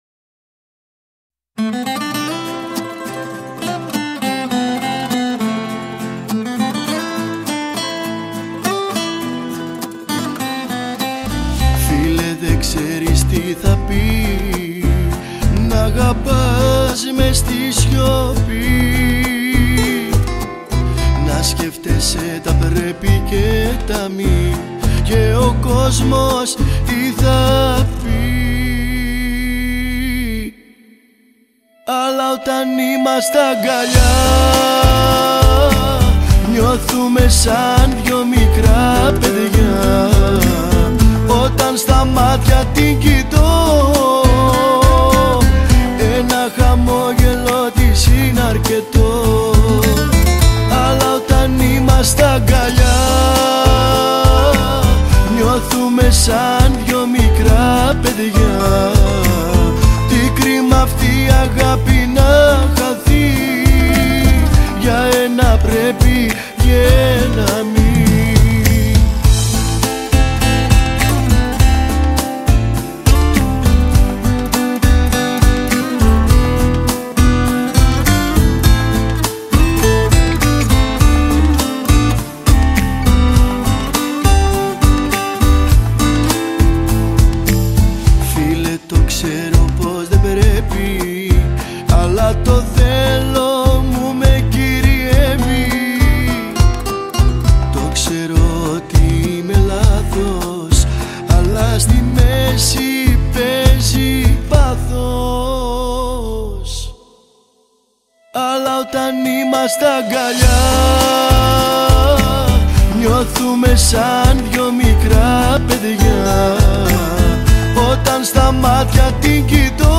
Жанр: ΠΟΠ και ΛΑΪΚΆ